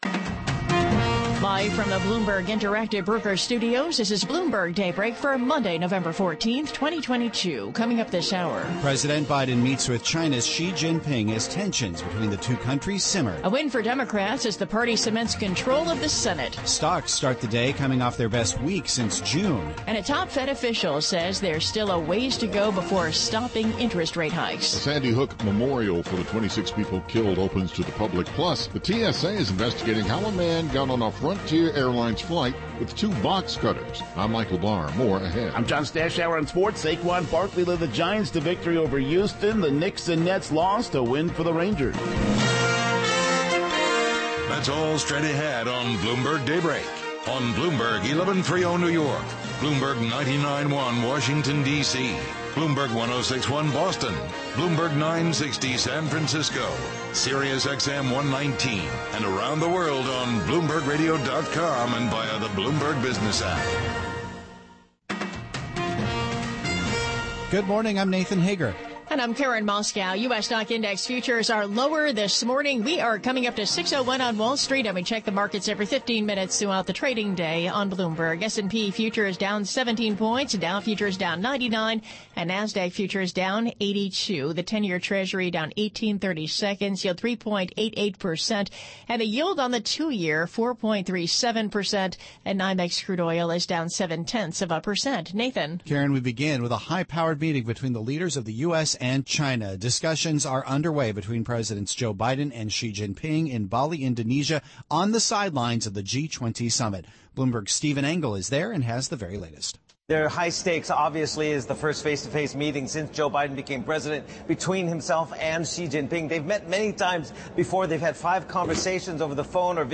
Bloomberg Daybreak: November 14, 2022 - Hour 2 (Radio)